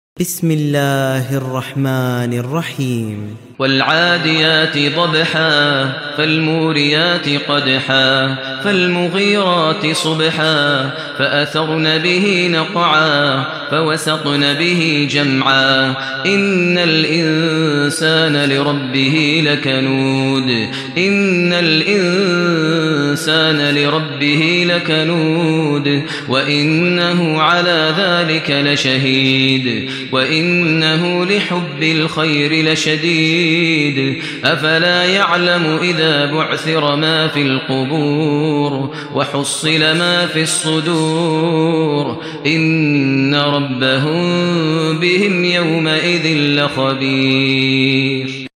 ترتیل سوره عادیات با صدای ماهر المعیقلی
100-Maher-Al-Muaiqly-Surah-Al-Adiyat.mp3